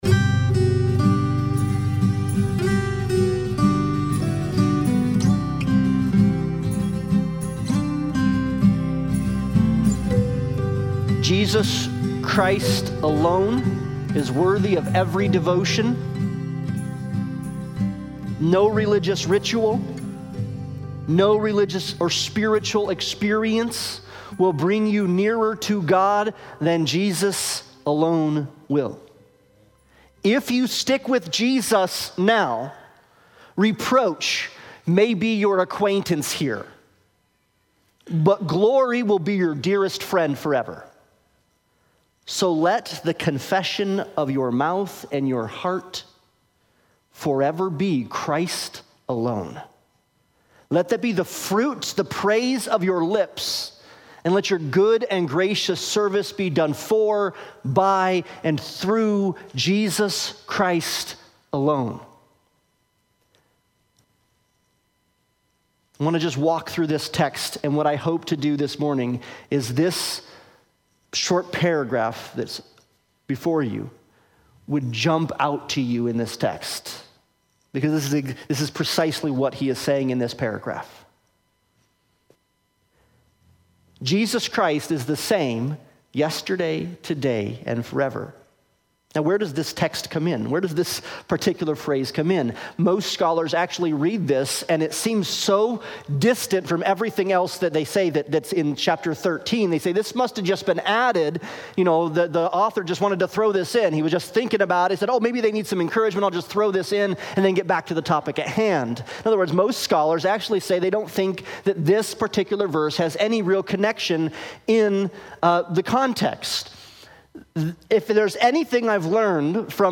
Hebrews 13:8-16 Service Type: Sunday Morning Worship « Glorification To the Elect Exiles